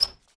Foley Sports / Tennis / Court Squeak Generic A.wav
Court Squeak Generic A.wav